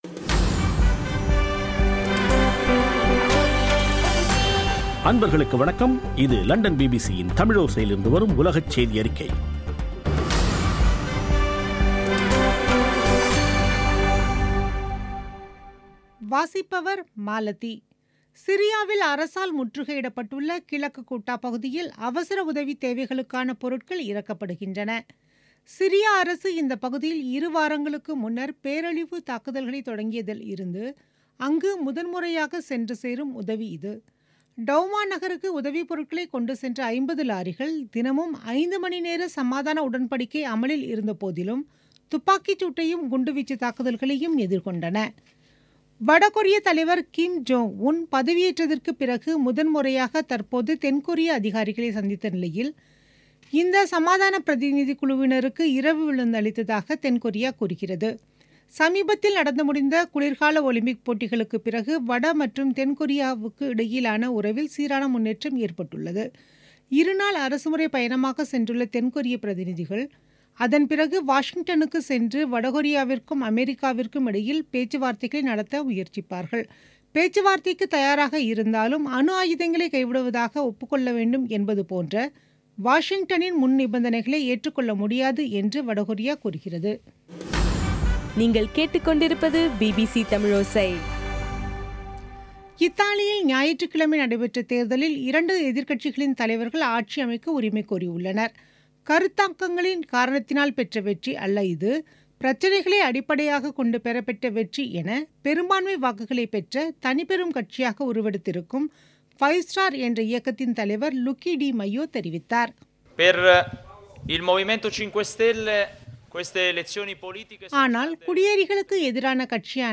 பிபிசி தமிழோசை செய்தியறிக்கை (05/03/2018)